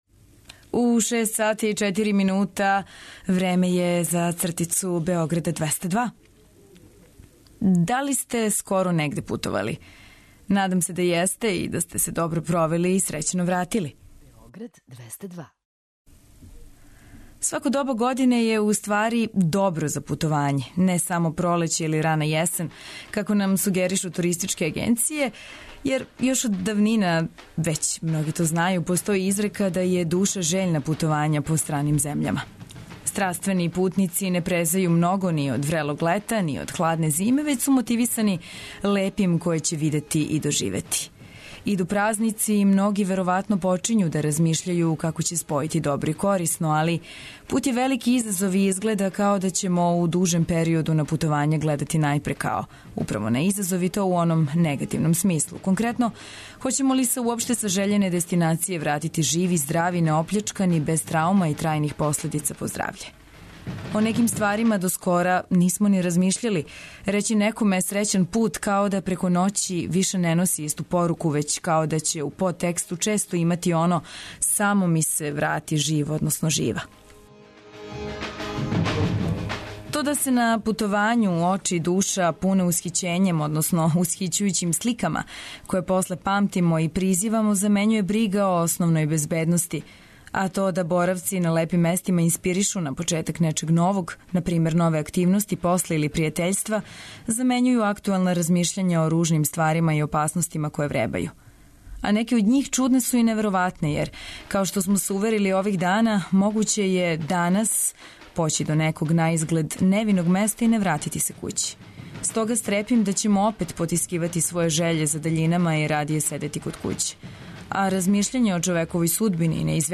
Ранораниоци и сви који воле да су на ногама рано ујутру, али и они који то не воле а морају, имају прилику да се разбуђују уз нашу "гимнастику" смехом, добру музику, сервисне информације и различите радијске прилоге типичне за "Устанак".